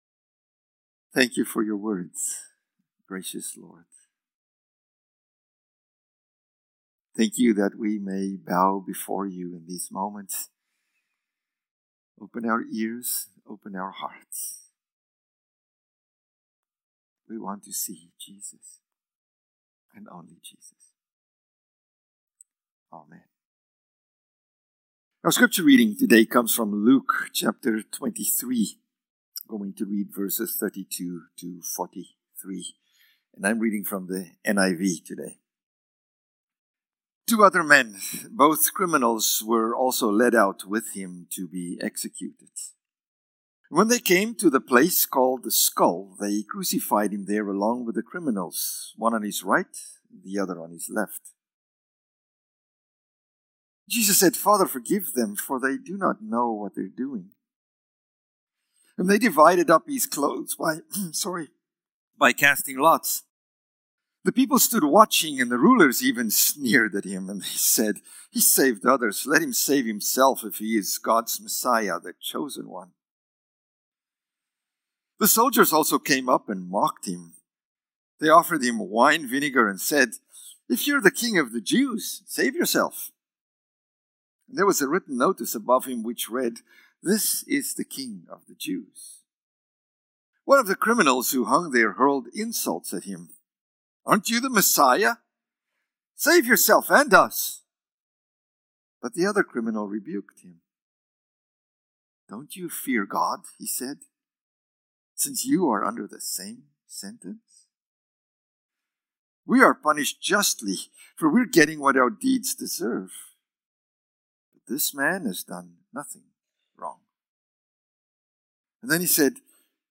Good-Friday-Sermon.mp3